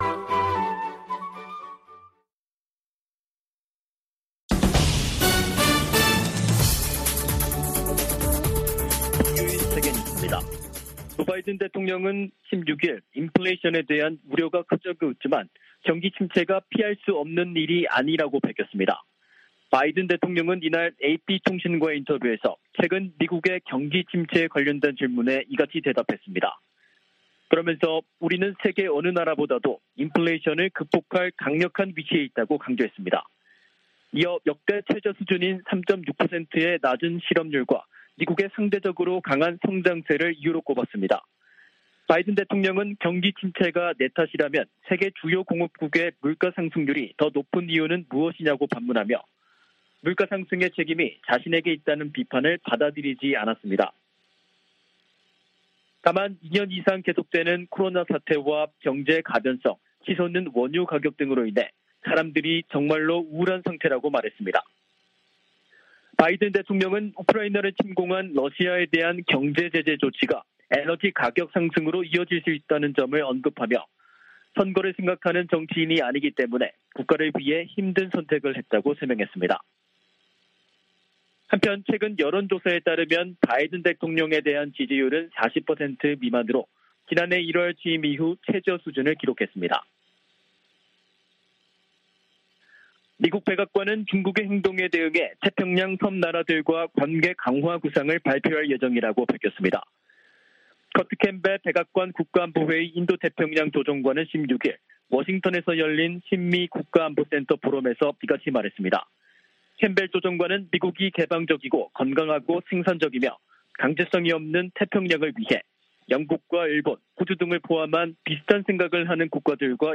VOA 한국어 간판 뉴스 프로그램 '뉴스 투데이', 2022년 6월 17일 2부 방송입니다. 미 국무부가 대화와 외교로 북한 핵 문제를 해결한다는 바이든 정부 원칙을 거듭 밝혔습니다. 북한의 7차 핵실험 가능성이 계속 제기되는 가운데 미국과 중국이 이 문제를 논의하고 있다고 백악관 고위 당국자가 밝혔습니다. 미국의 전문가들은 북한의 풍계리 핵실험장 4번 갱도 움직임은 폭발력이 다른 핵실험을 위한 것일 수도 있다고 분석했습니다.